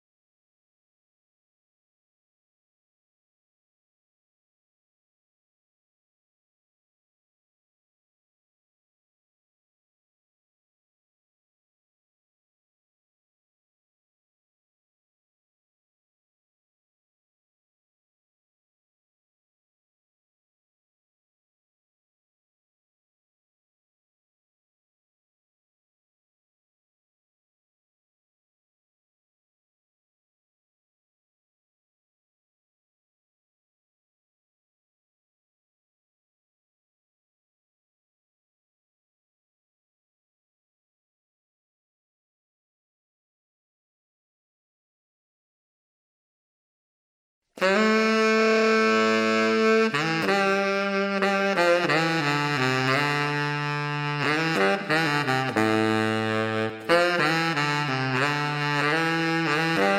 Sax Solo Stem Only Pop (1980s) 3:53 Buy £1.50